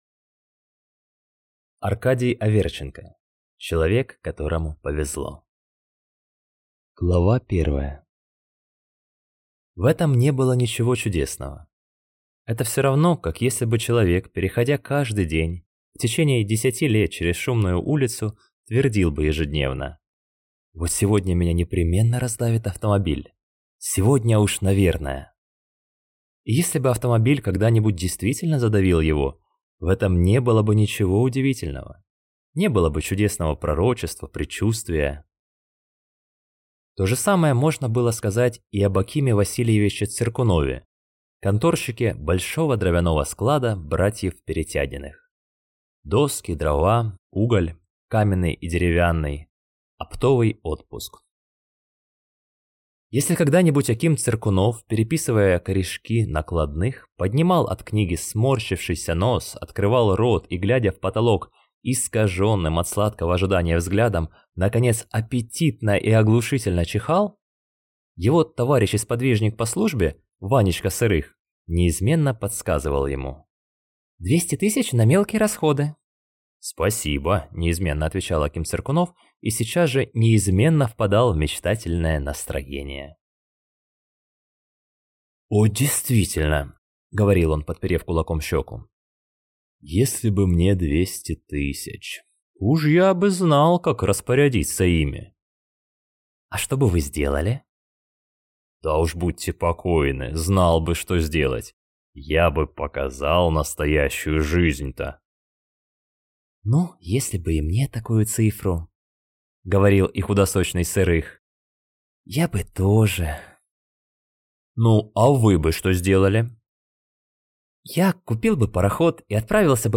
Аудиокнига Человек, которому повезло | Библиотека аудиокниг